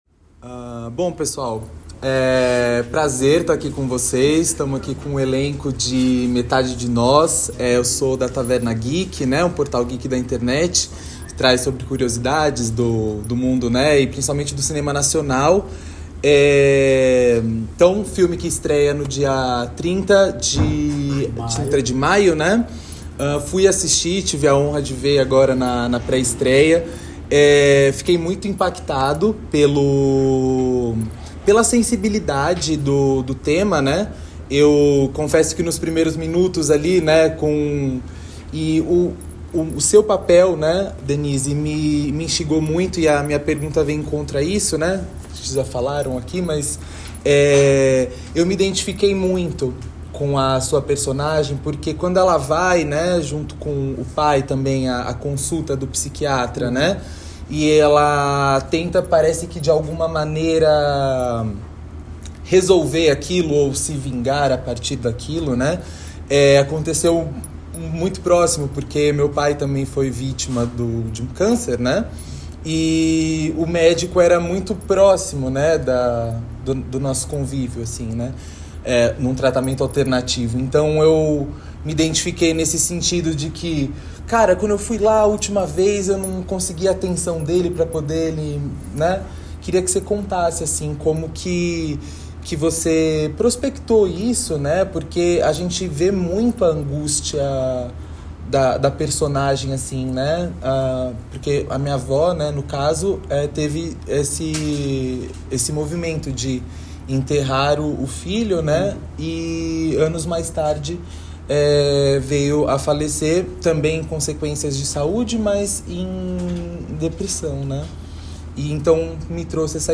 Você pode conferir parte da nossa entrevista abaixo, seguida da mesma completa em forma de áudio.